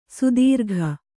♪ sudīrgha